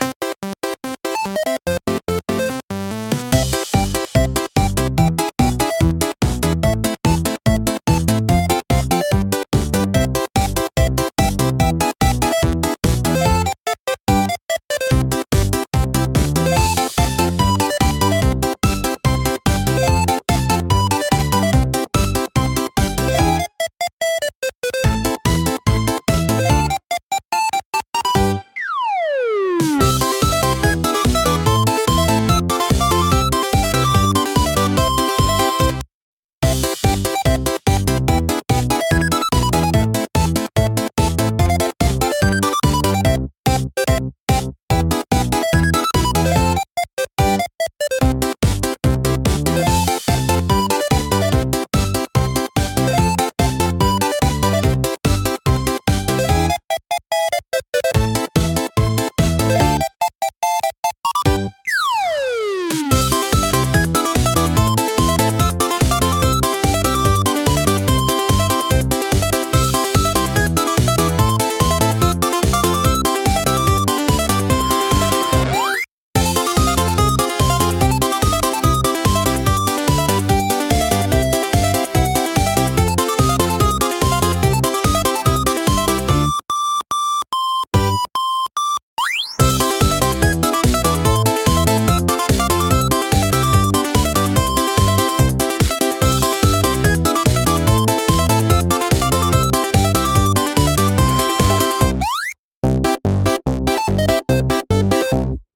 アクションは、明るくコミカルなシンセポップを特徴とするオリジナルジャンルです。
軽快なリズムと親しみやすいメロディーが、楽しく活発な雰囲気を作り出します。